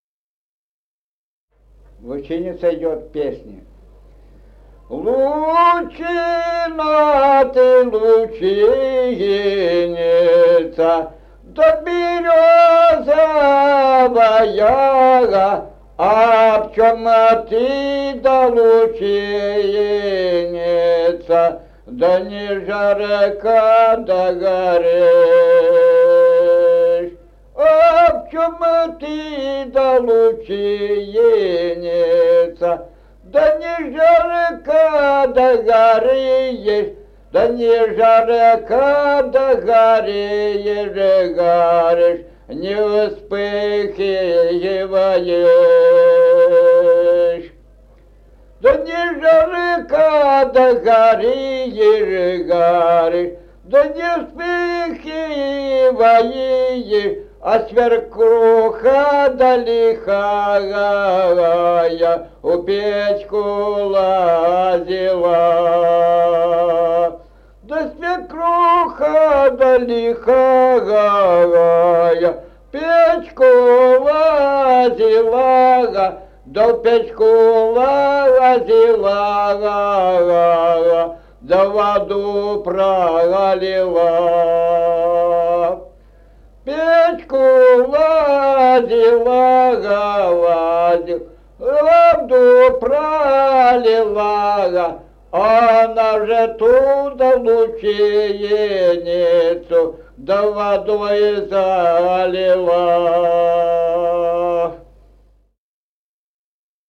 Музыкальный фольклор села Мишковка «Лучина, ты лучиница», лирическая, репертуар скрипача.